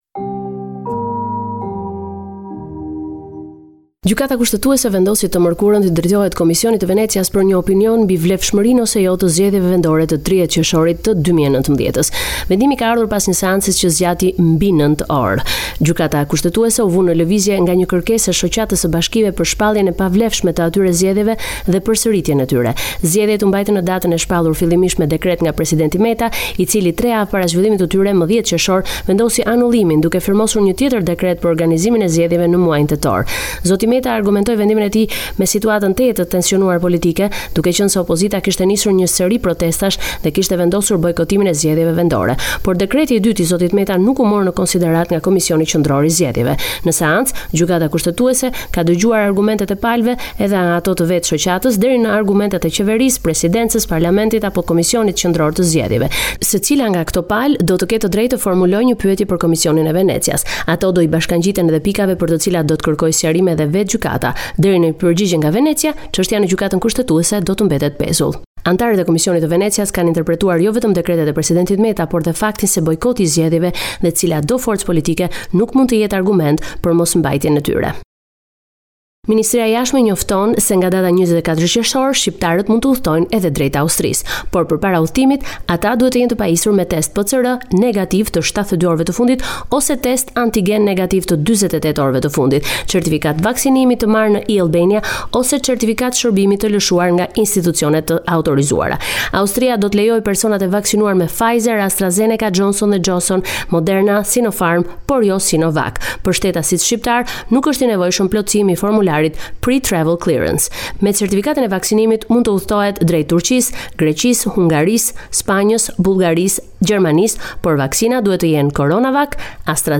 Raporti me te rejat me te fundit nga Shqiperia.